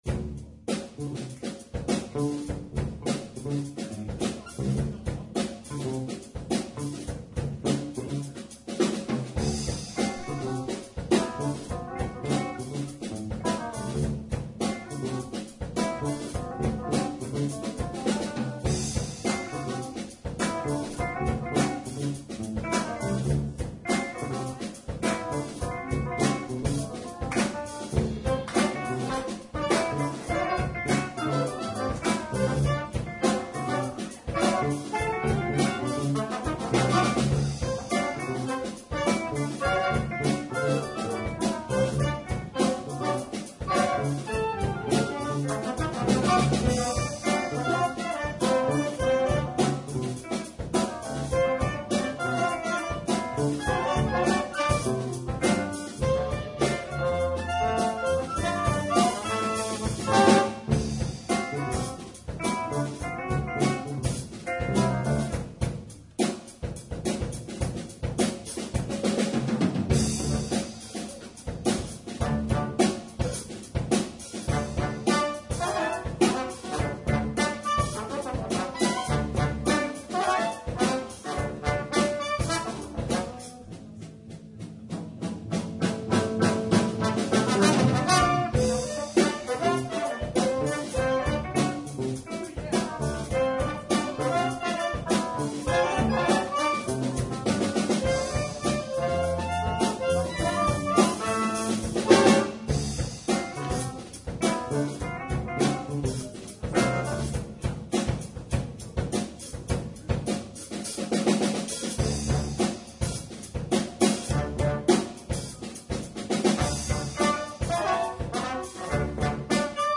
ARS - stage Jazz 2013